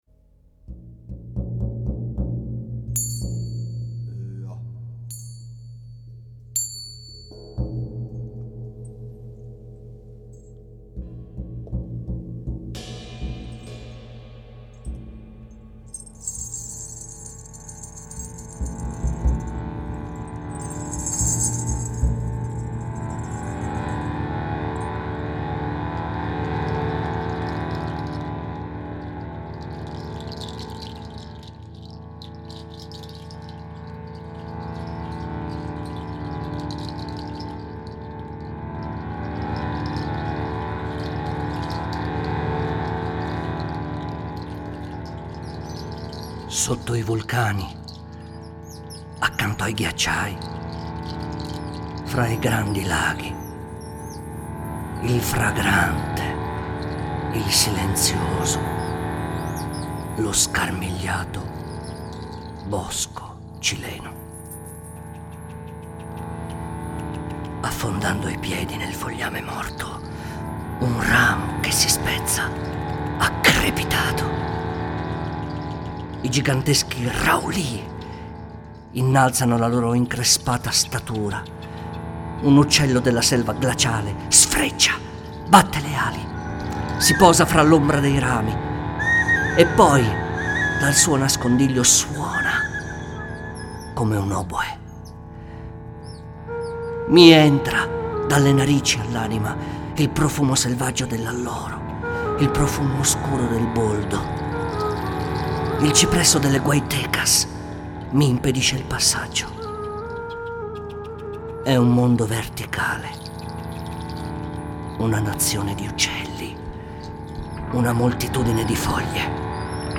bandoneon
voce recitante